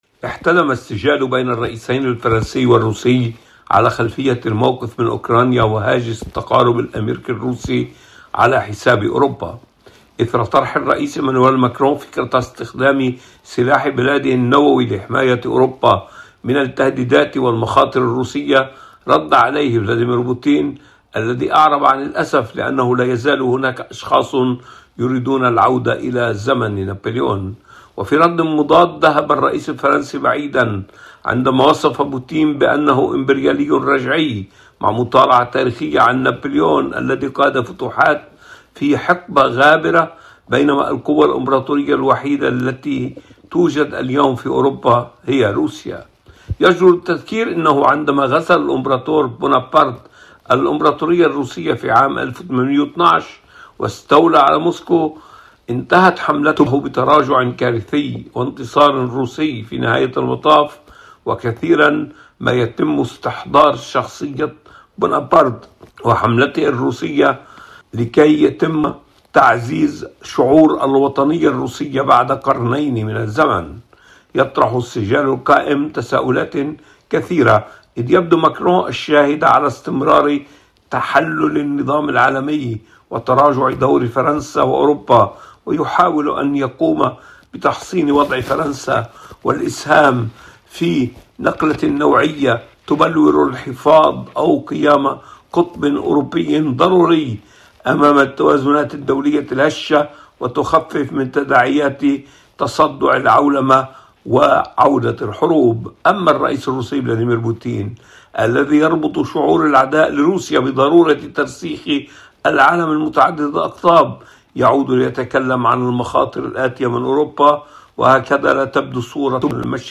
فقرة إخبارية تتناول خبراً أو حدثاً لشرح أبعاده وتداعياته، تُبَثّ على مدار الأسبوع عند الساعة الرابعة والربع صباحاً بتوقيت باريس ويُعاد بثها خلال الفترات الإخبارية الصباحية والمسائية.